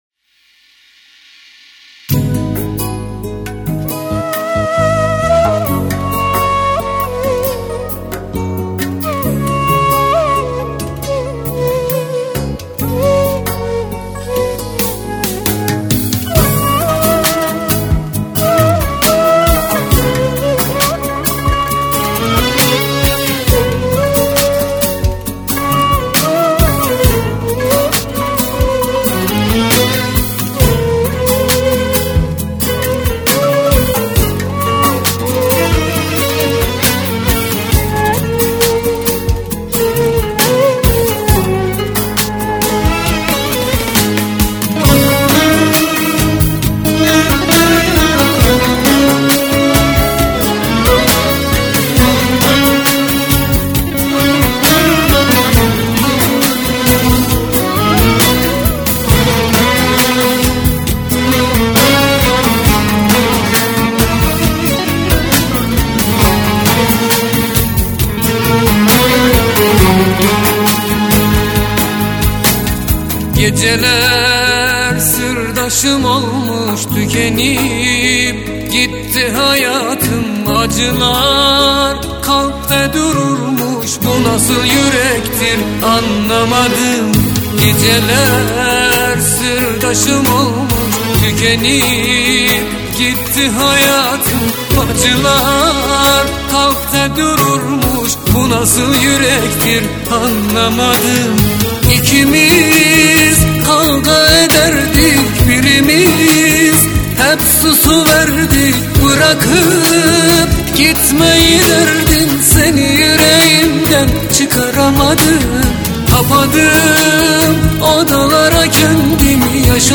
آهنگ آذری و ترکی
Mahalli